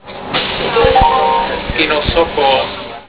Hlášení zastávek a mimořádností:
Běžná hlášení Stáhnout
jihlava_kinosokol.wav